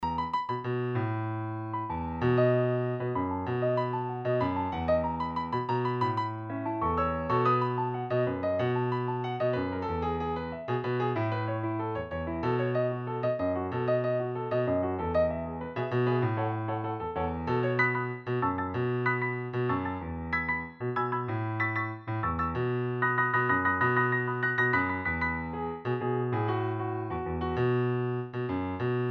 Piano
Tono original: Bb